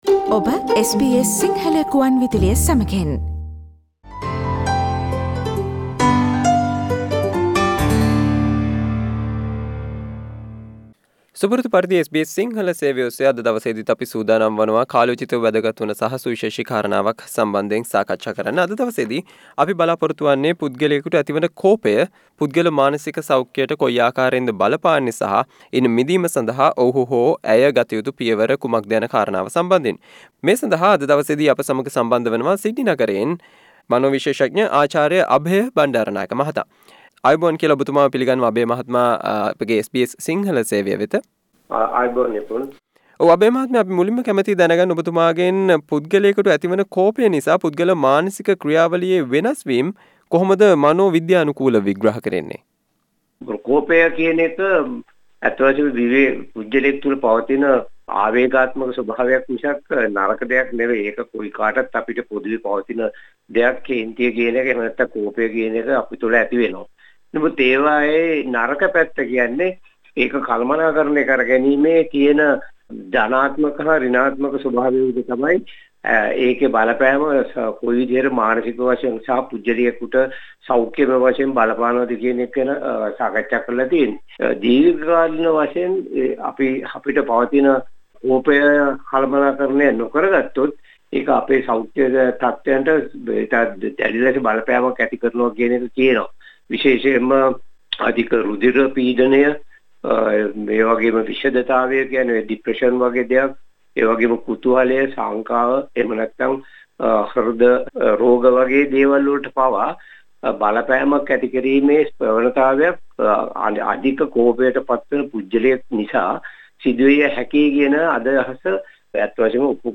SBS සිංහල සේවය සිදුකල සාකච්චාවට සවන්දෙන්න